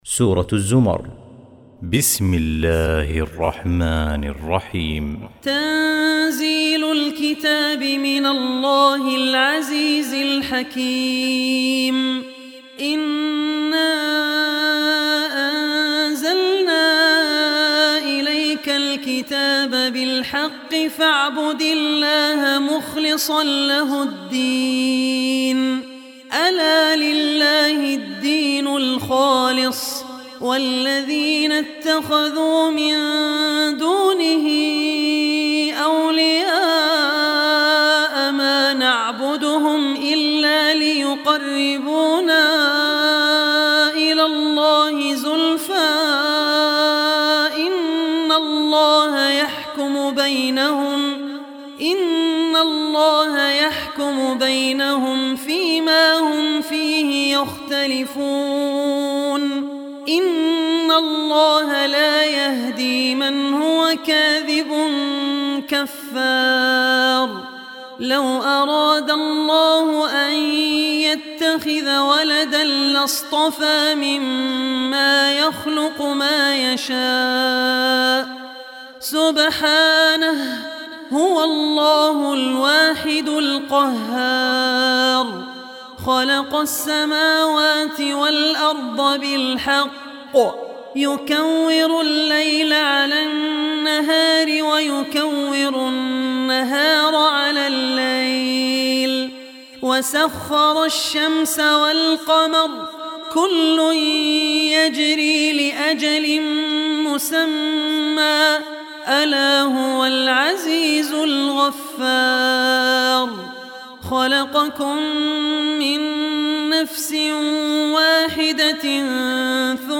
Surah Az-Zumar Recitation by Abdur Rehman Al Ossi
Surah Az-Zumar, listen online mp3 tilawat / recitation in the voice of Sheikh Abdur Rehman Al Ossi.